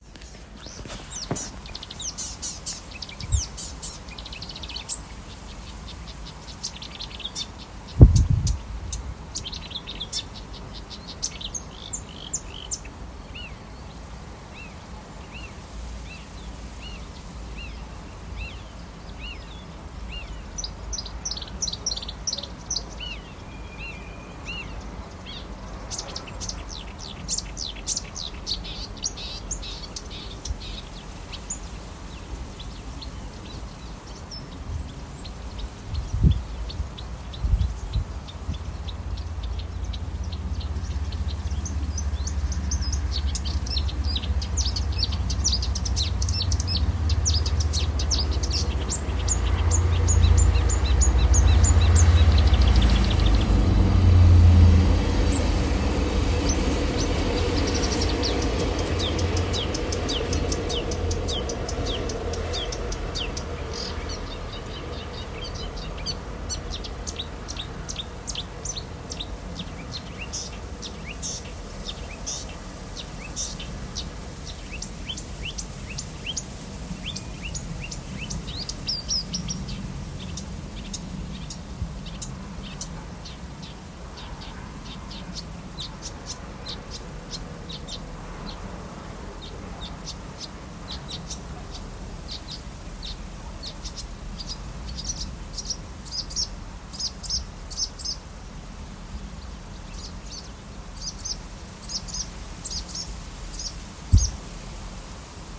De bosrietzanger en de trein
Wat een muzikale energie! Hij is ook goed in het imiteren van andere vogels, op deze opname hoor je onder meer zijn vrijwel perfecte weergave van het geluid van buizerd en boerenzwaluw. Het geluid van de trein die komt langsrijden op de spoordijk is van de trein zelf.